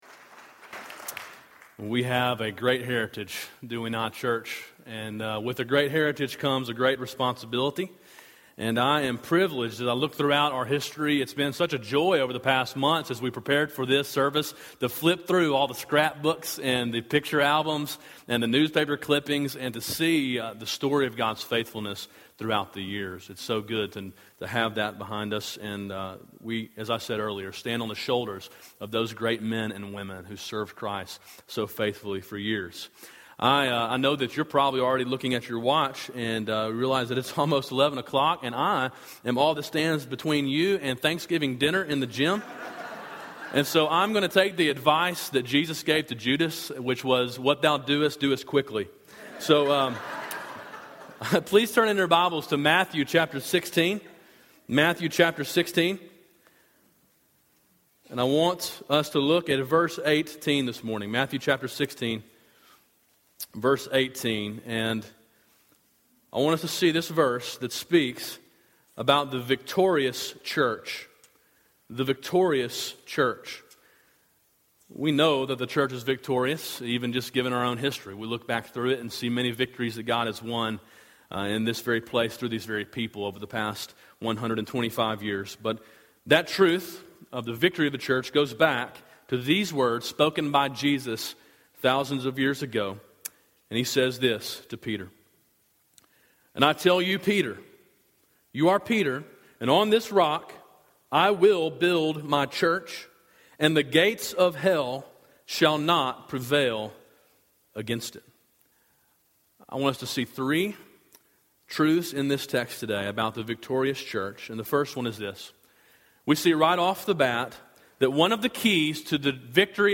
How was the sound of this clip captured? A short sermon preached during a celebration service on the 125th anniversary of Calvary Baptist Church, November 18, 2012.